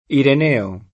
vai all'elenco alfabetico delle voci ingrandisci il carattere 100% rimpicciolisci il carattere stampa invia tramite posta elettronica codividi su Facebook Ireneo [ iren $ o ; sp. iren % o ] pers. m. — sim., in it., il cogn.